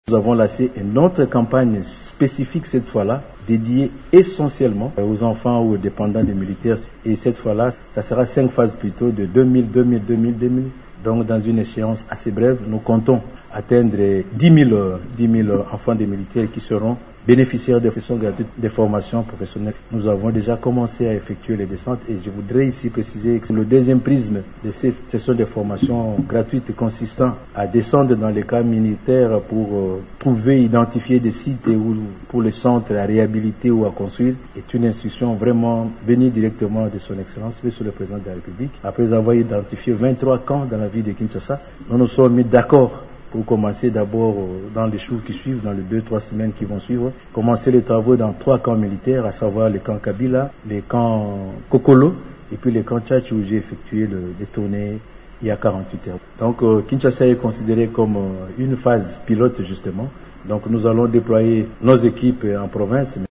Cette information a été communiquée par le ministre de la Formation professionnelle, Marc Ekila, lors d'un briefing de presse tenu à Kinshasa, en compagnie de son collègue de la Communication et Médias, Patrick Muyaya.
Vous pouvez écouter le ministre ici :